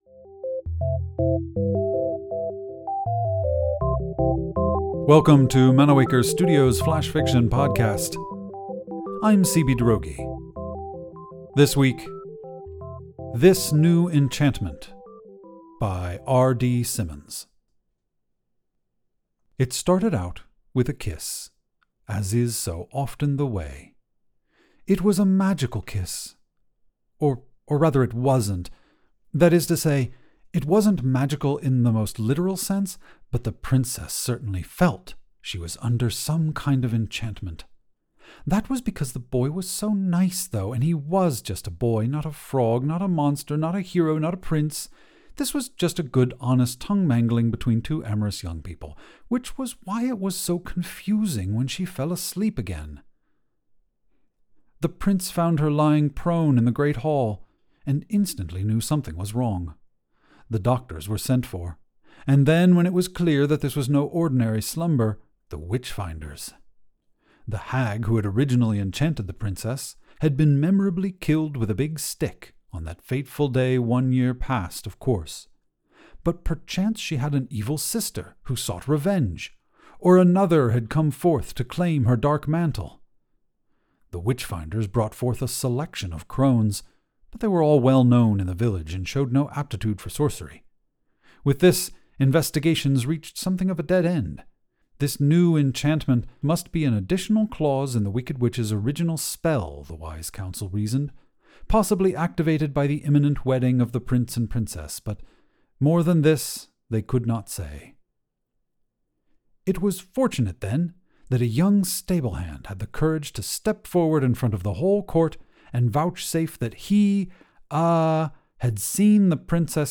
The Flash Fiction Podcast Theme Song is by Kevin McCleod